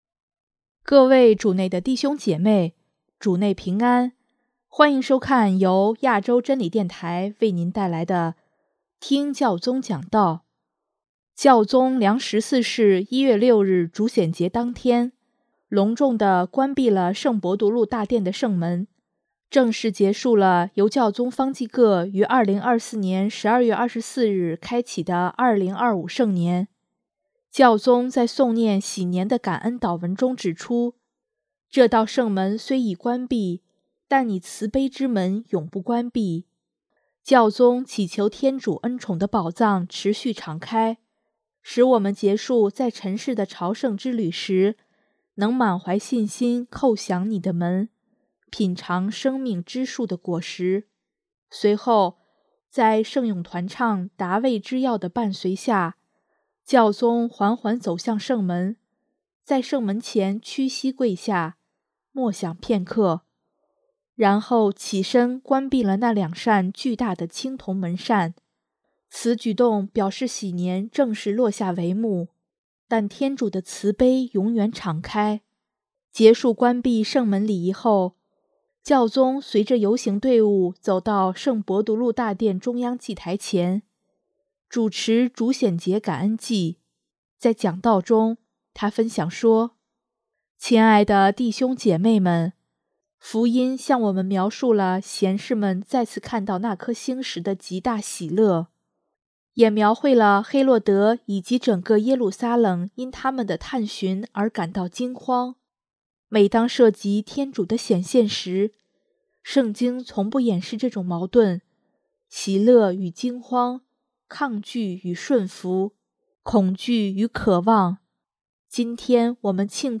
【听教宗讲道】|教宗关闭圣伯多禄大殿圣门：天主慈悲之门永不关闭